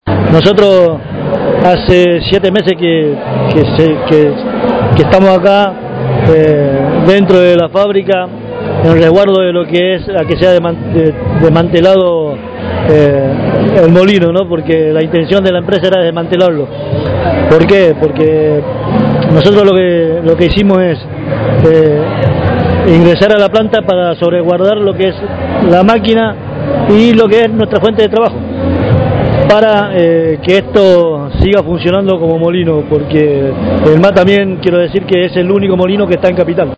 Compartimos la entrevista